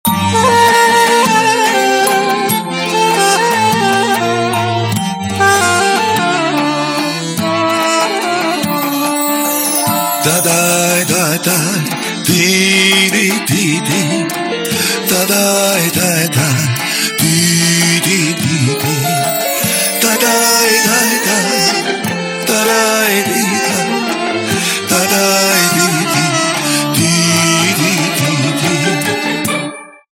• Качество: 194, Stereo
мужской вокал
инструментальные
восточные
духовые